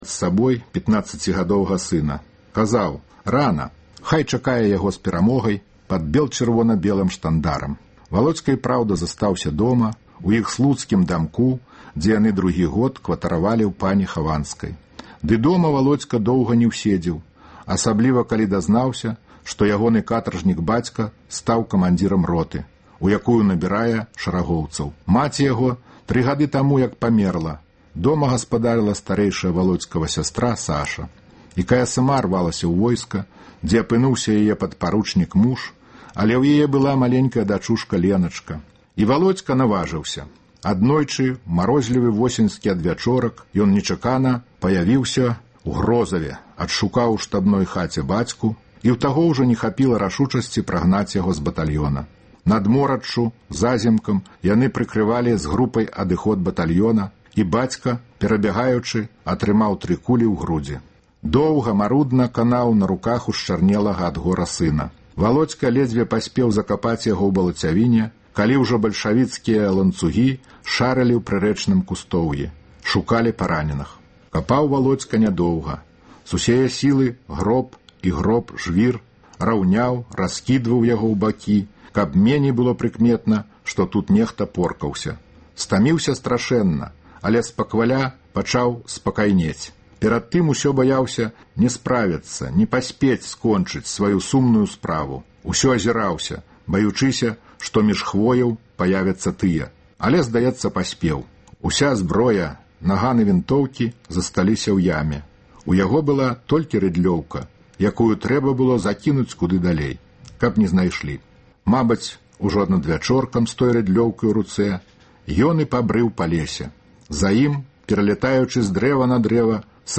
Вядомыя людзі Беларусі чытаюць свае ўлюбёныя творы Васіля Быкава. Сяргей Законьнікаў чытае ўрывак з апавядаеньня Васіля Быкава “На чорных лядах”